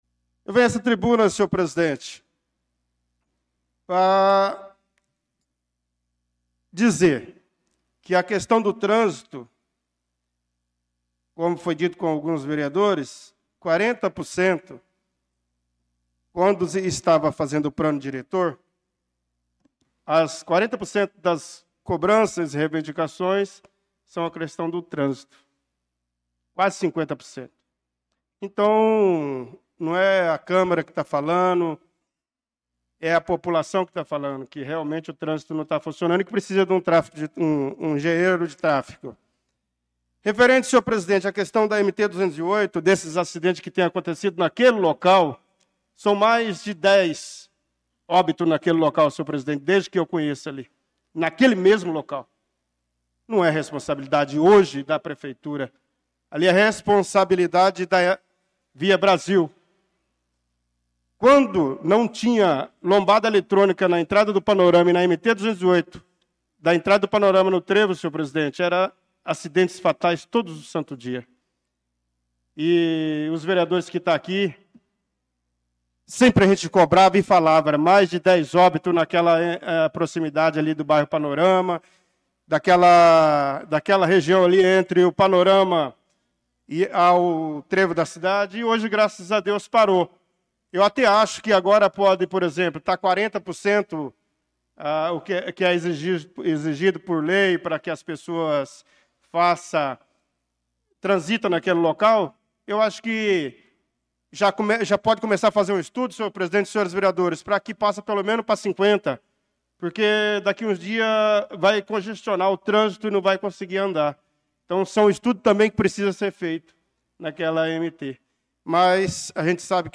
Pronunciamento do vereador Bernardo Patrício na Sessão Ordinária do dia 25/08/2025.